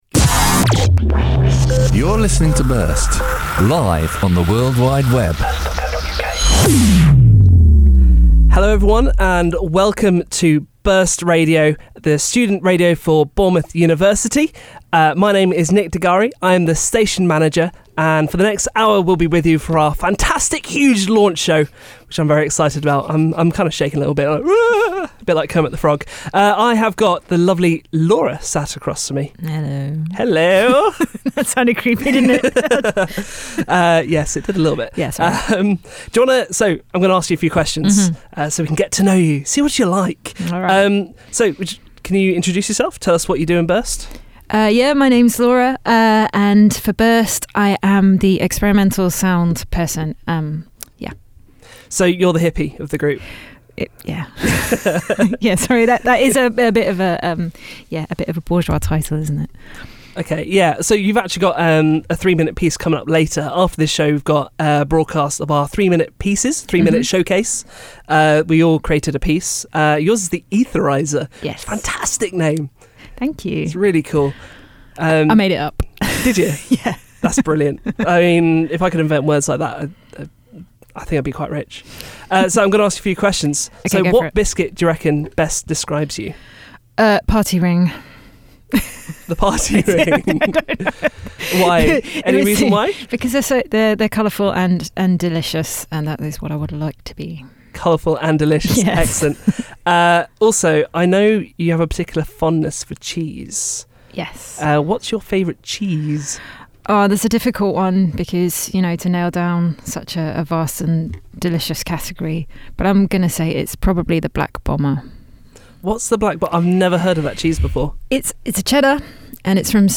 Meet each member by listening back to their launch show where each person took time to interview the next. The show was mixed up with variations of song choices from each individual.